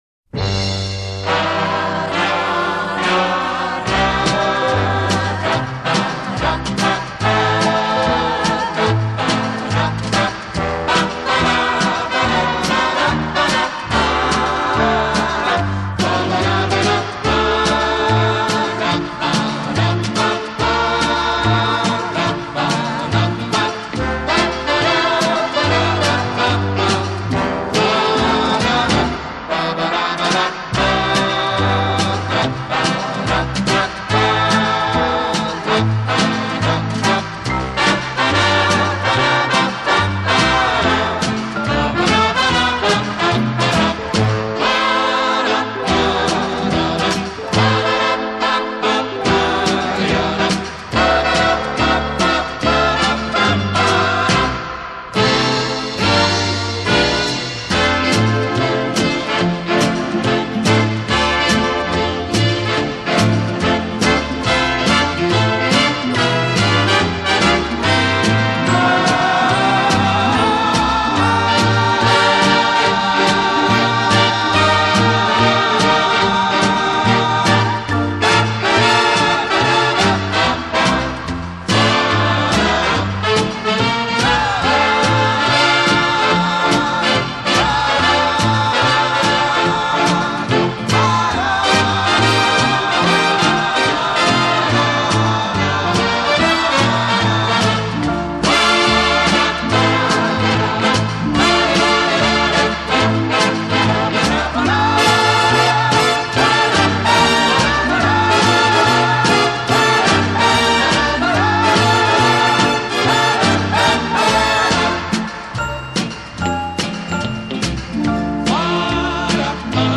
Format:Vinyl, LP, Stereo
Genre:Easy Listening